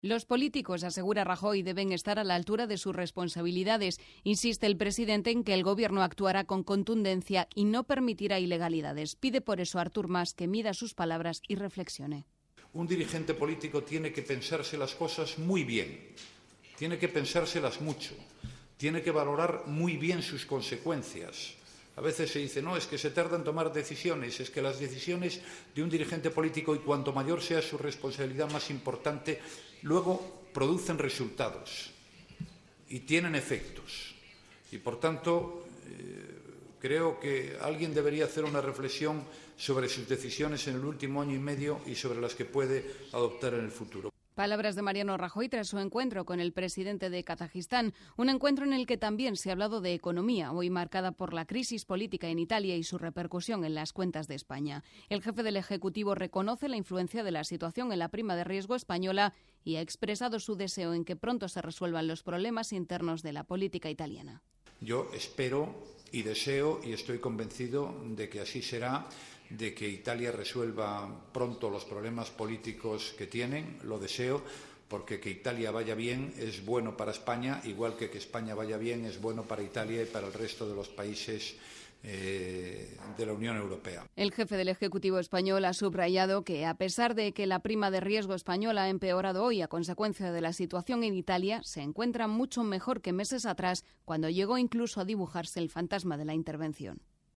Rajoy ha respondido de esta forma en rueda de prensa en la capital de Kazajistán, Astaná, donde se encuentra de visita oficial, a la determinación de Mas, avalada por el Parlament, de llevar al Congreso la reclamación de una consulta sobre el futuro de Cataluña.